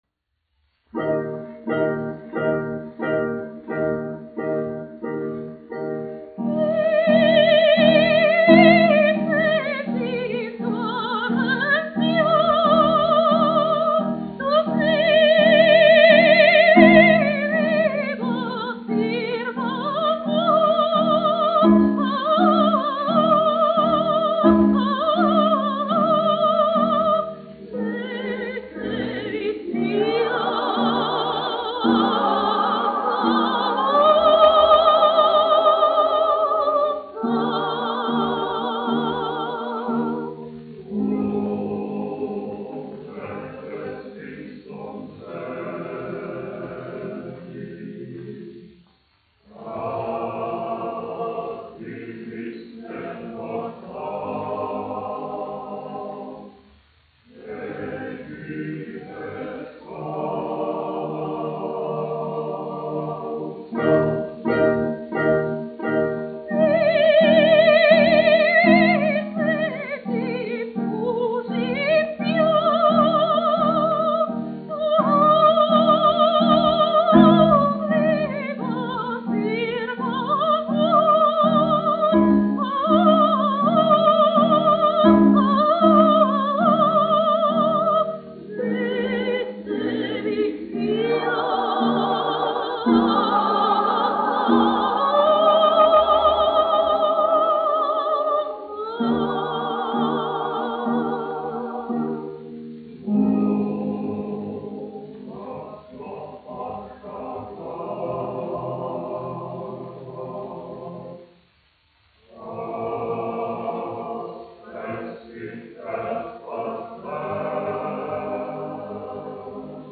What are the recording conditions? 1 skpl. : analogs, 78 apgr/min, mono ; 25 cm Skaņuplate